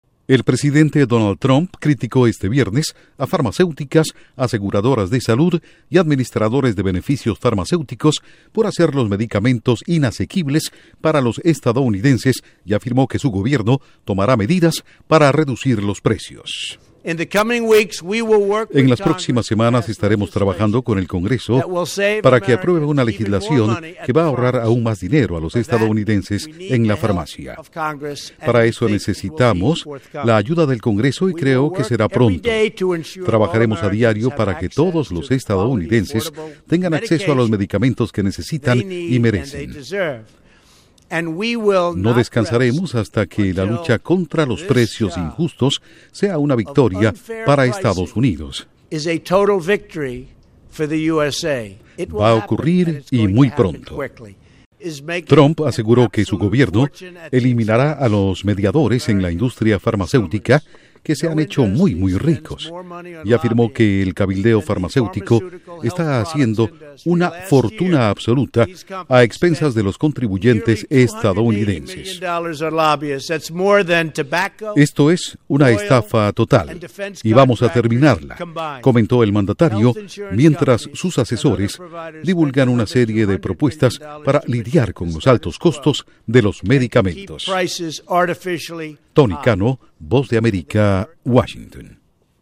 La Casa Blanca impulsa proyecto para reducir el precio de los medicamentos en Estados Unidos. Informa desde la Voz de América en Washington